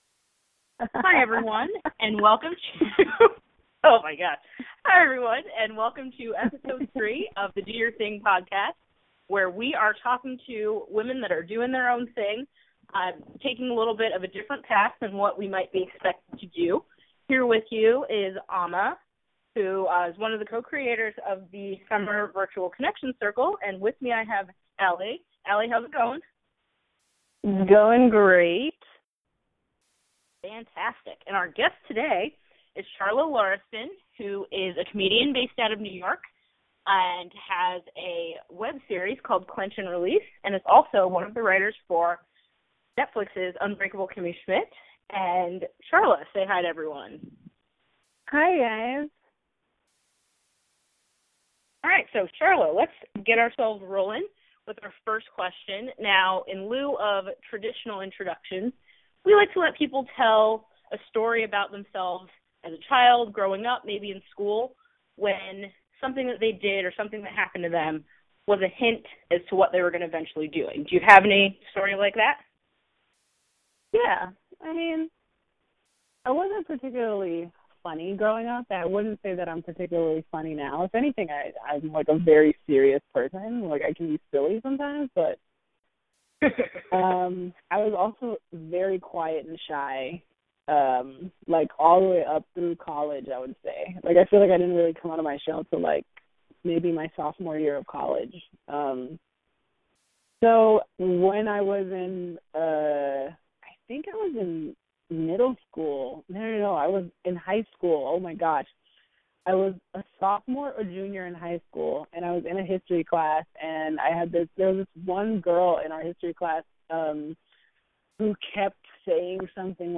This third episode starts with laughter, and for good reason!